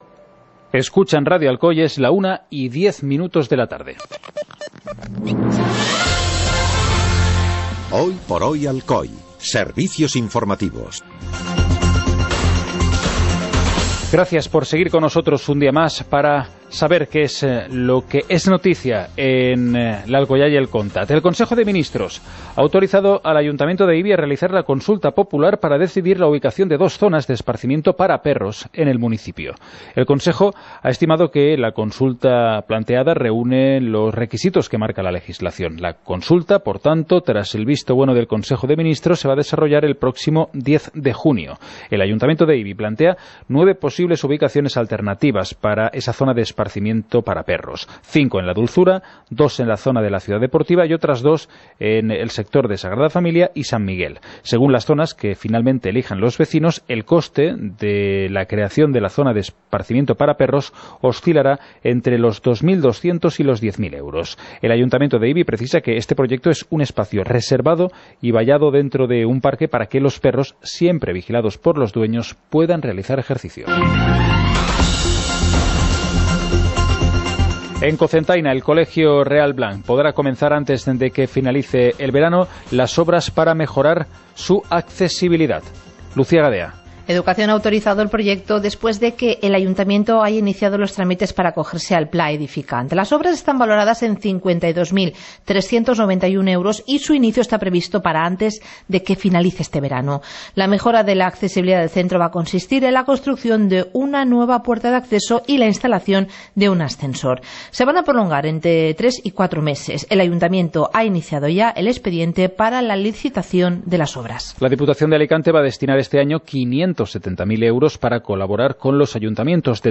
Informativo comarcal - lunes, 28 de mayo de 2018